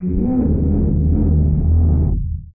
MOAN EL 13.wav